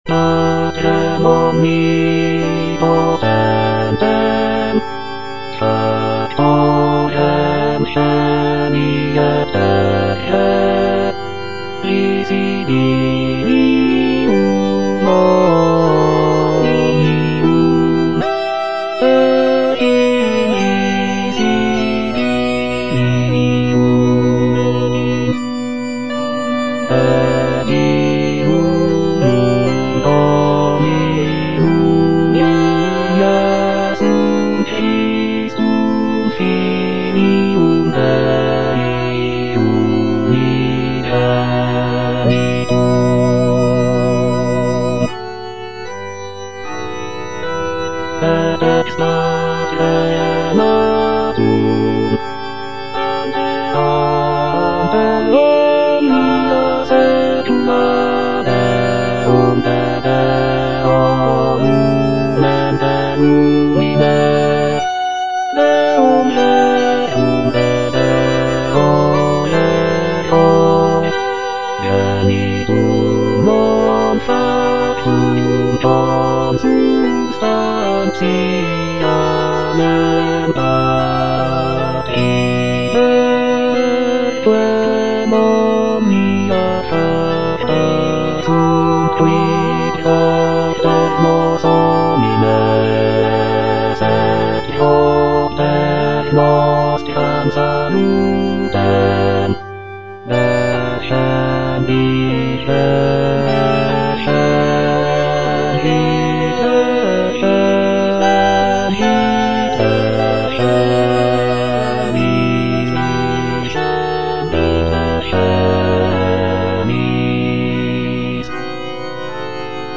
J.G. RHEINBERGER - MISSA MISERICORDIAS DOMINI OP.192 Credo - Bass (Emphasised voice and other voices) Ads stop: auto-stop